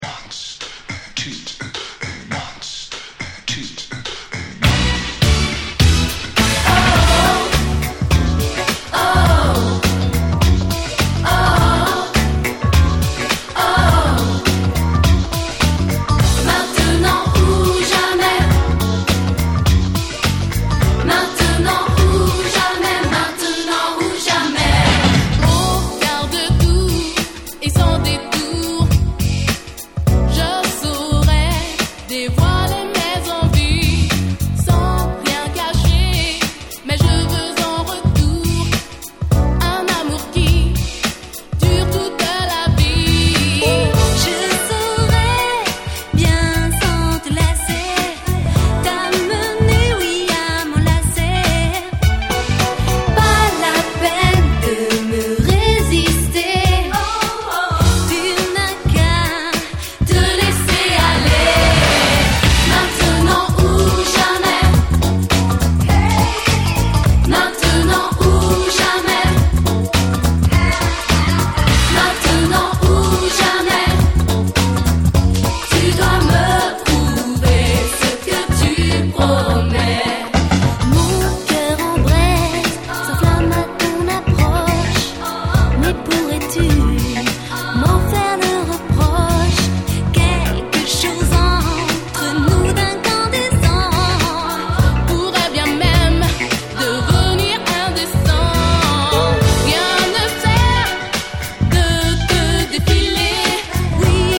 97' Nice キャッチーR&B !!
GroovyなシンセにキャッチーなVocalが最高！
(Extended Club Mix)